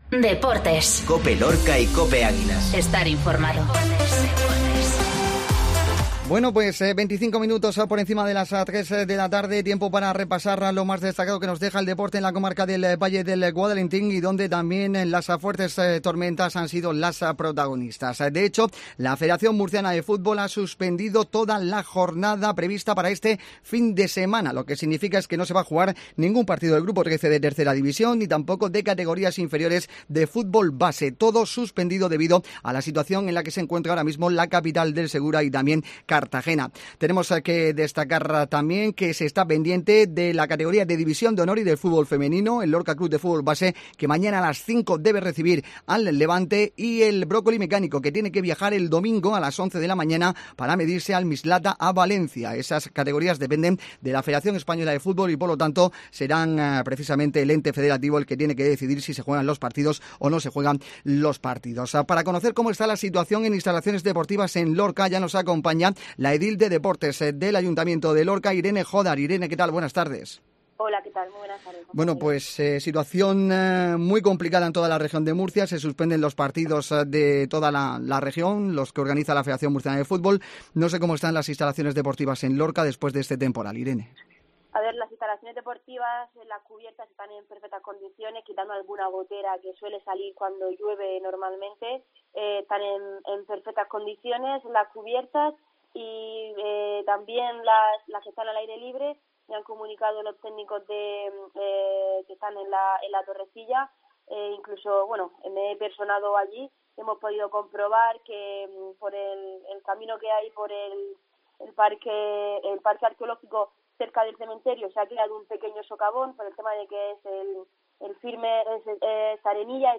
Entrevista Irene Jódar, edil Deportes